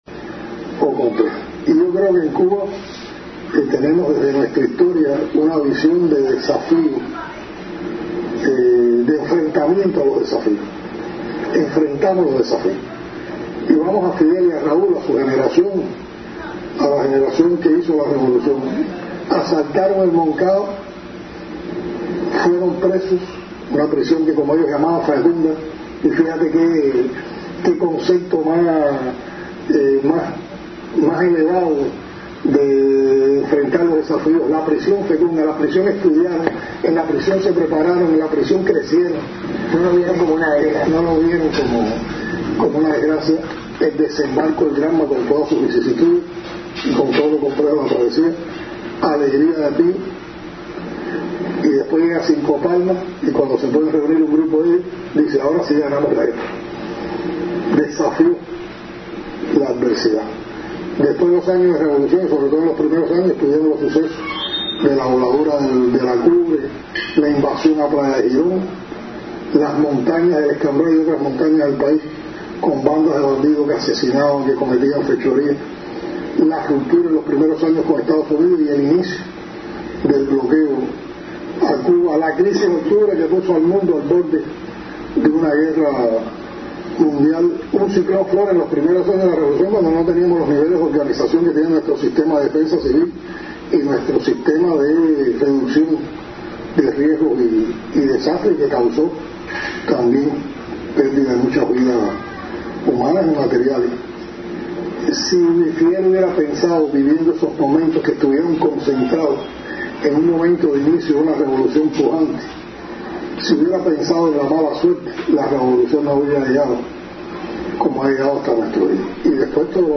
Presidente cubano realiza declaraciones en la Mesa Redonda sobre desafíos que enfrenta el país - Radio Majaguabo
Así expresó el Presidente Cubano en declaraciones al programa televisivo Mesa Redonda sobre los desafíos que enfrenta el país.